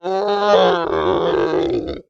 Скачивайте рыки, рев, тяжелое дыхание и крики фантастических существ в формате MP3.
Крик испытывающего боль монстра